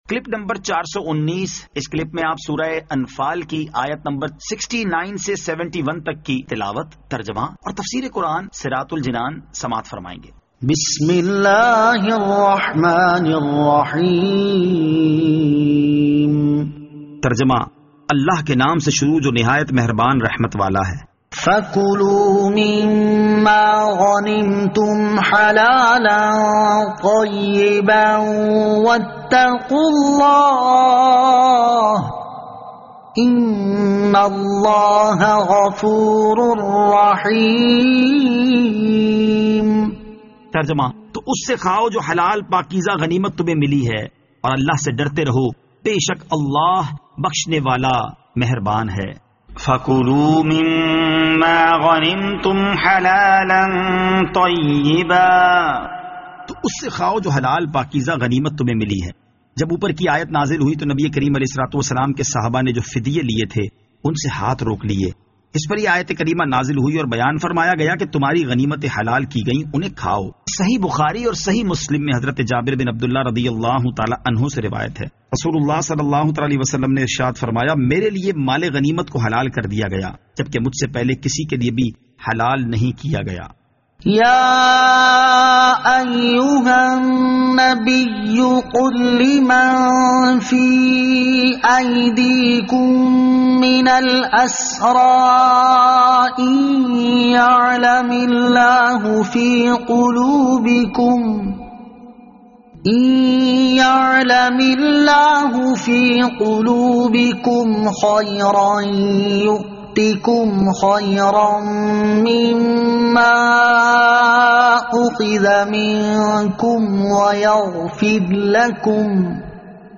Surah Al-Anfal Ayat 69 To 71 Tilawat , Tarjama , Tafseer